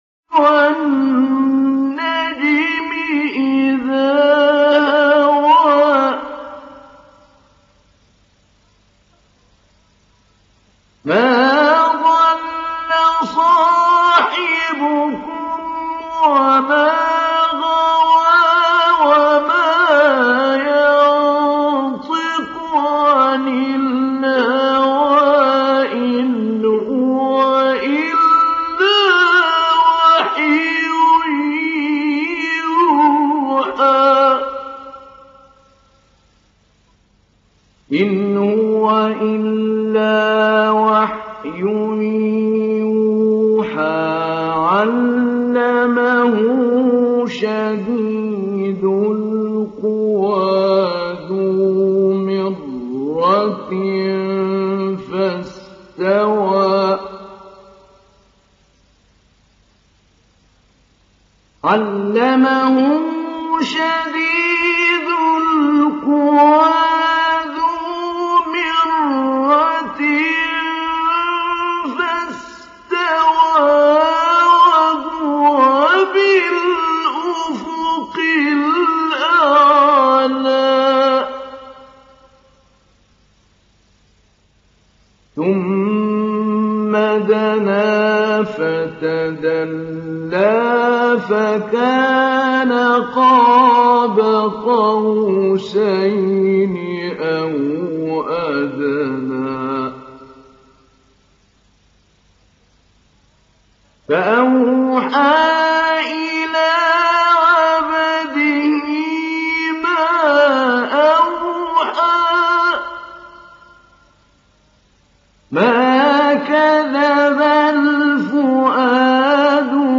Download Surat An Najm Mahmoud Ali Albanna Mujawwad